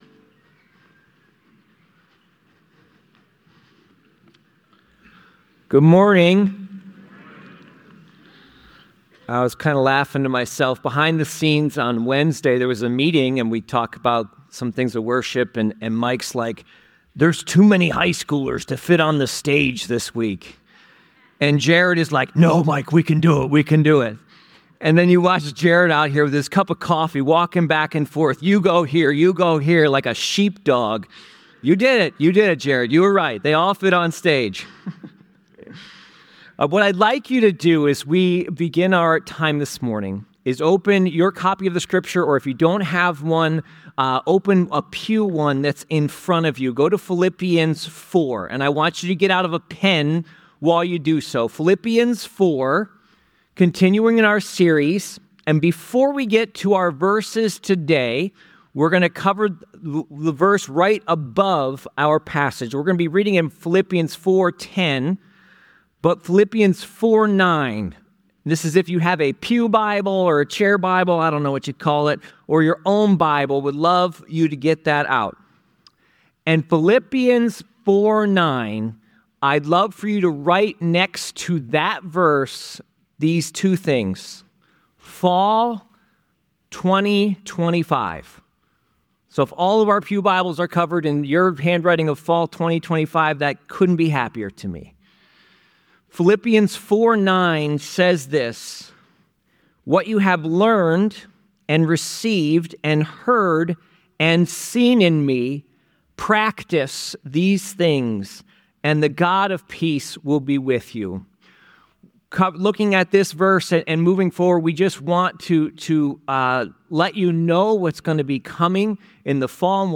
This sermon explores the concept of true contentment through biblical teachings, specifically focusing on Philippians 4:10-13.
The sermon closes with a prayer for the congregation to find joy and contentment in Christ amidst life's challenges.